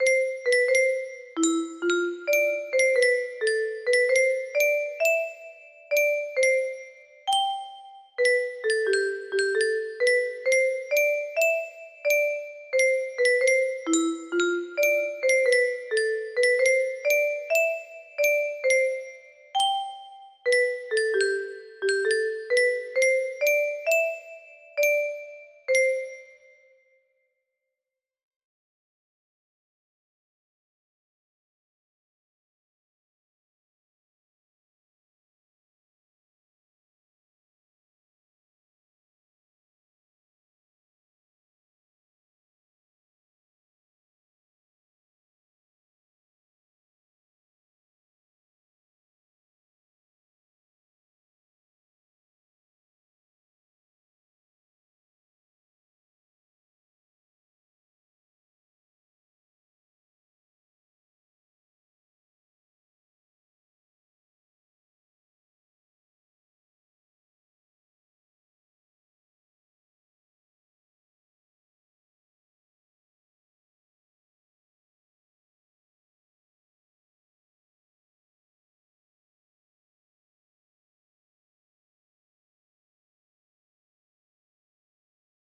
ndy music box melody